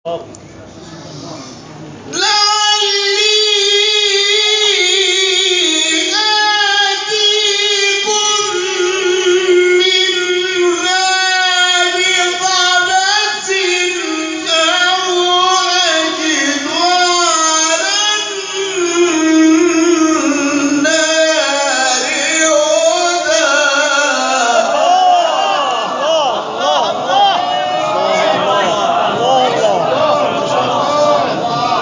گروه شبکه اجتماعی: فرازهای صوتی از قاریان ممتاز و تعدادی از قاریان بین‌المللی کشورمان را می‌شنوید.